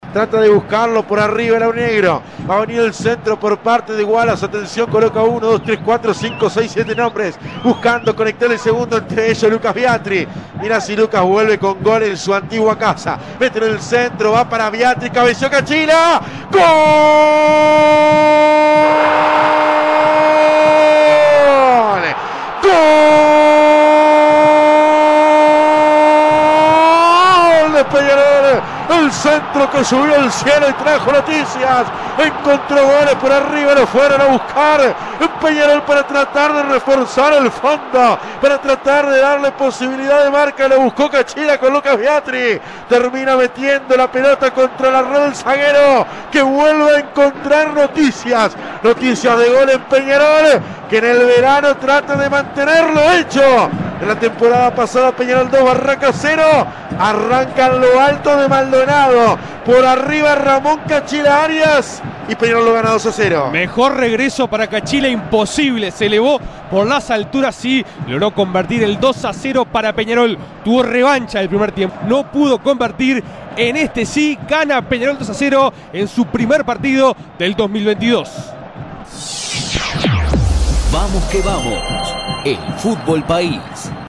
Relato Vamos que vamos: